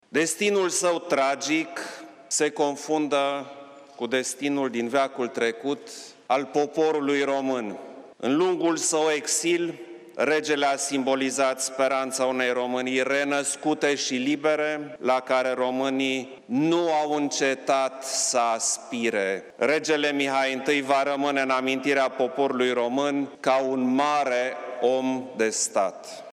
Regele Mihai a simbolizat speranţa unei Românii renăscute şi libere, la care românii nu au încetat să aspire şi va rămâne în amintirea poporului român ca un mare om de stat, a declarat luni preşedintele Klaus Iohannis.
La ședința solemnă participă Preşedintele Klaus Iohannis, principesa Margareta, Custodele coroanei regale, şi Patriarhul Daniel .